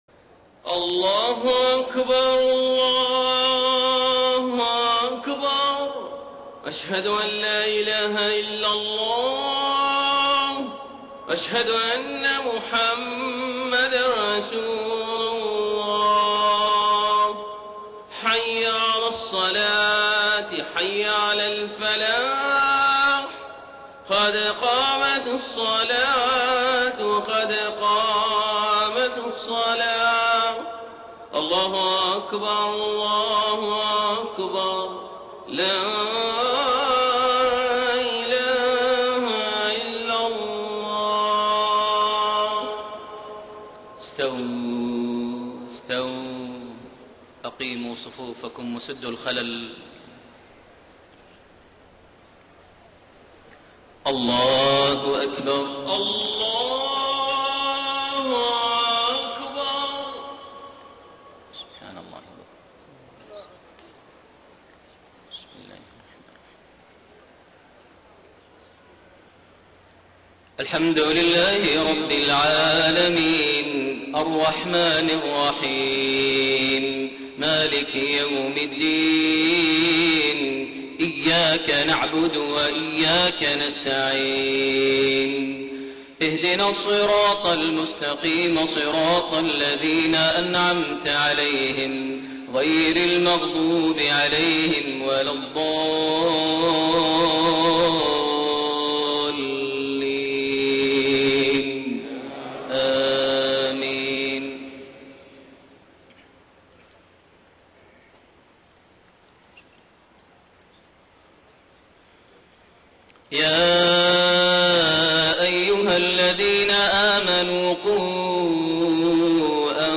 Maghrib prayer from Surah At-Tahrim > 1433 H > Prayers - Maher Almuaiqly Recitations